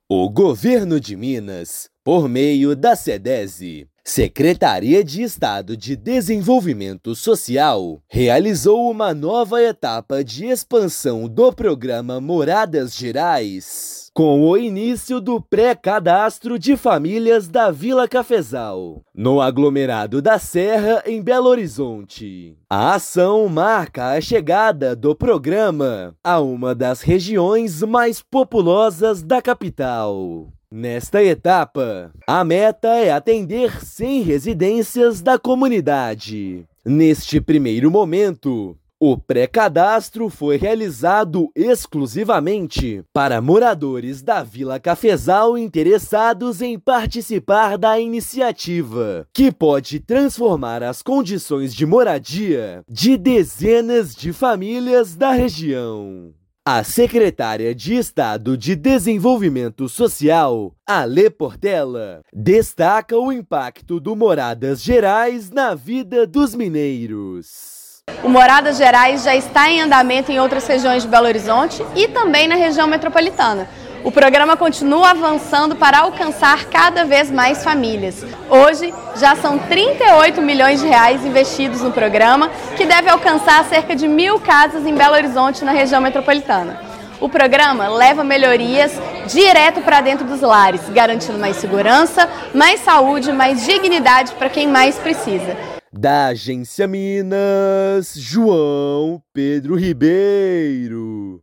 Programa leva melhorias habitacionais e mais dignidade a famílias em uma das maiores comunidades da capital mineira. Ouça matéria de rádio.